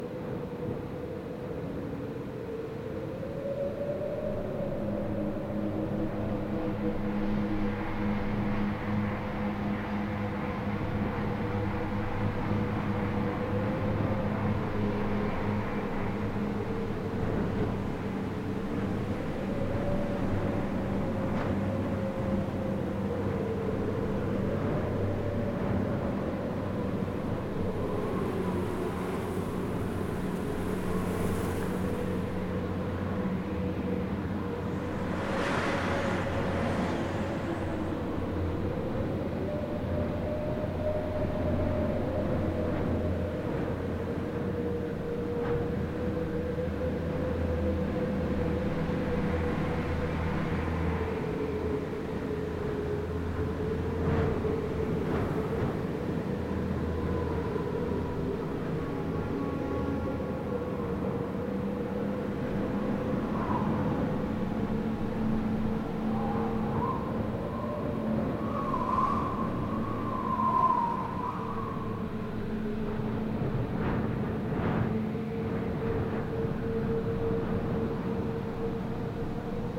На этой странице собраны звуки песчаной бури – от далёкого гула до оглушительного вихря.